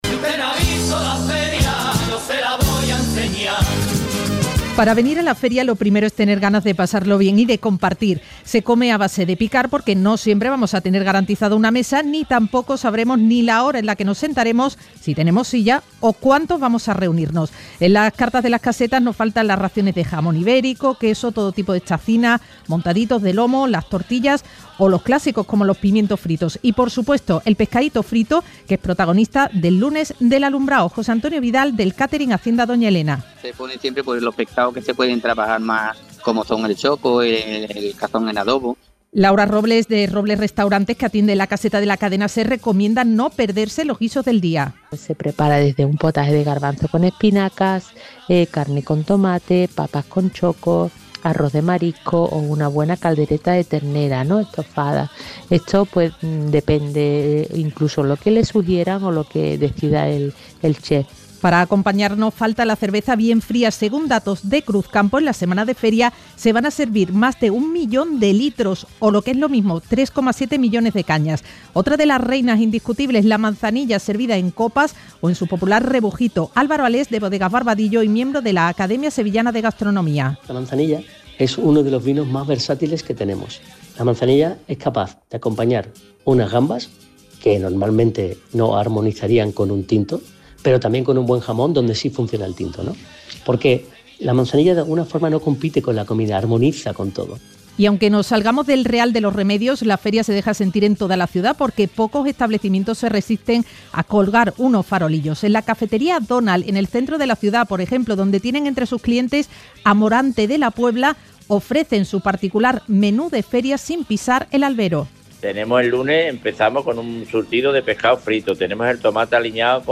Reportaje | Guía de supervivencia gastronómica para la Feria de Abril